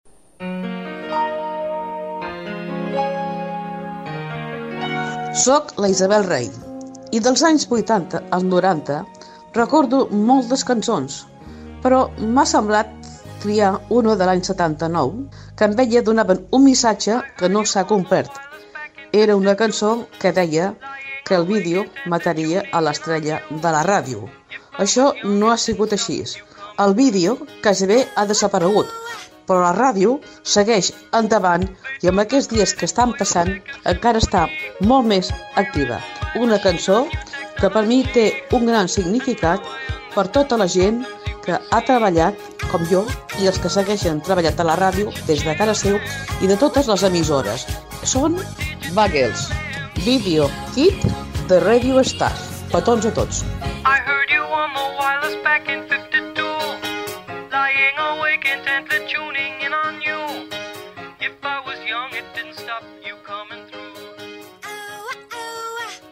Musical
Presentació d'un tema musical